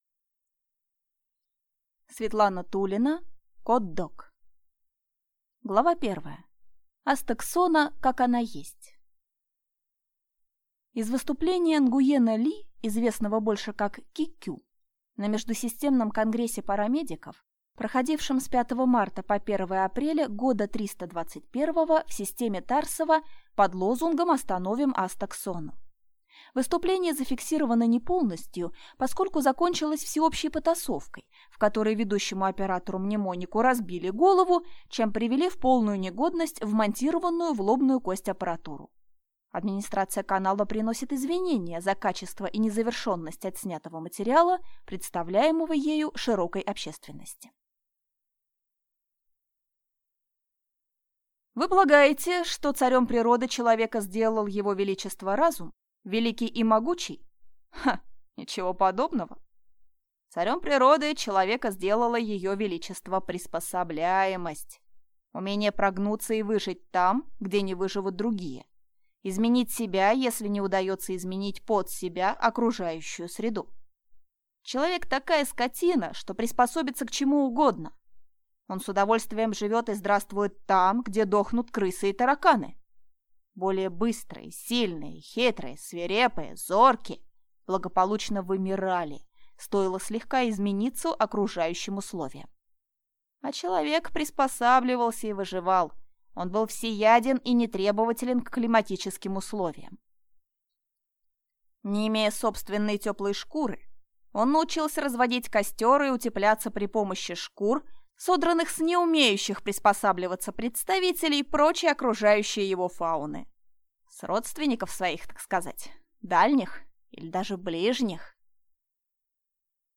Аудиокнига Котдог | Библиотека аудиокниг
Прослушать и бесплатно скачать фрагмент аудиокниги